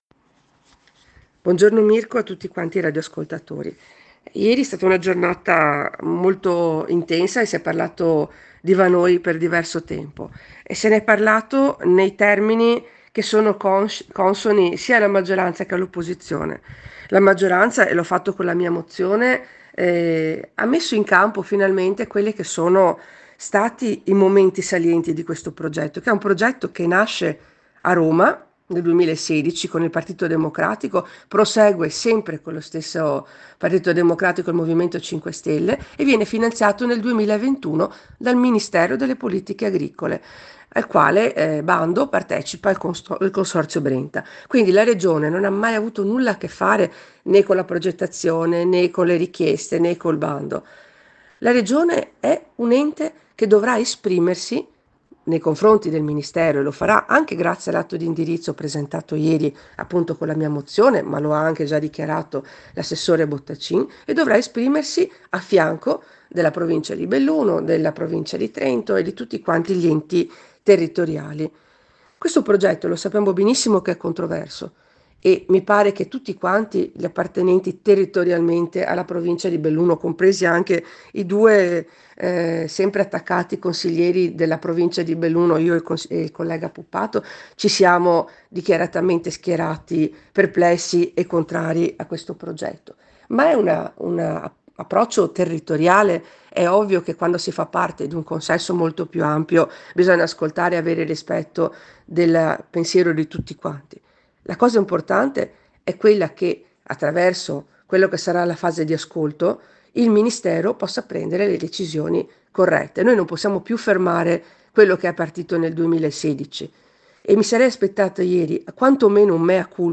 IL CONSIGLIERE REGIONALE, SILVIA CESTARO